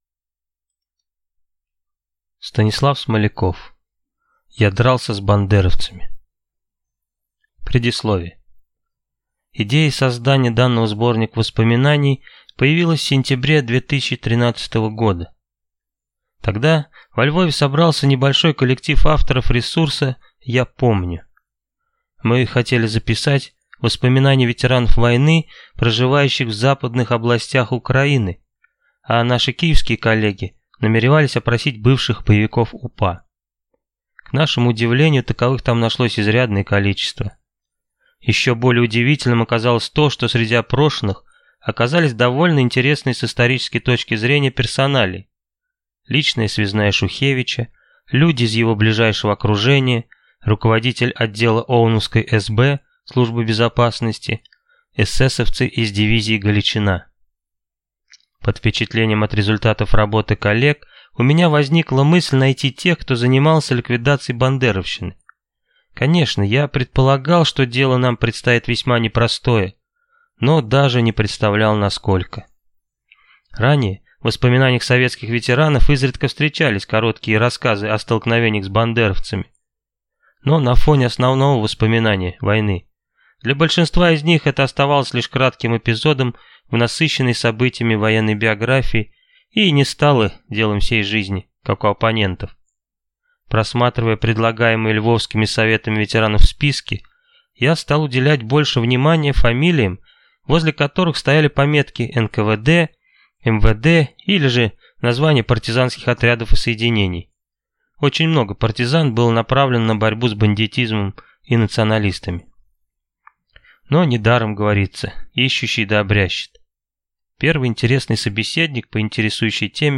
Аудиокнига Я дрался с бандеровцами | Библиотека аудиокниг